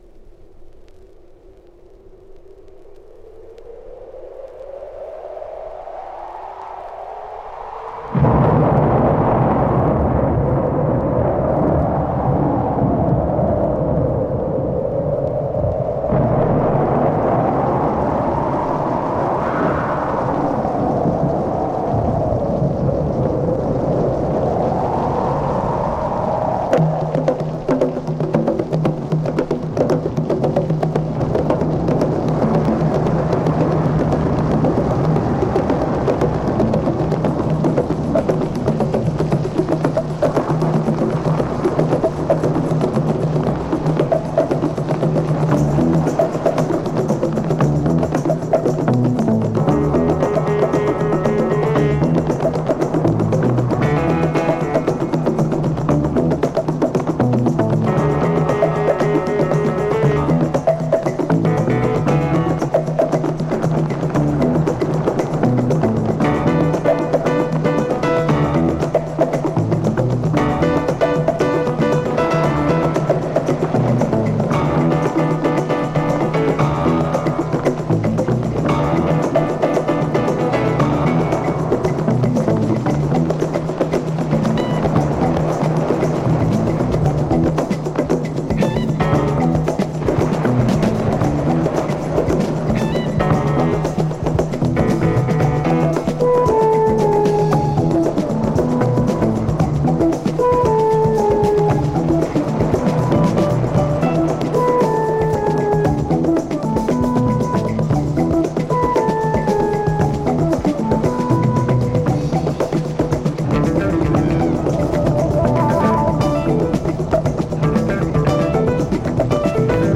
Italian Early Disco & Fusion!
【ITALY】【FUNK】【FUSION】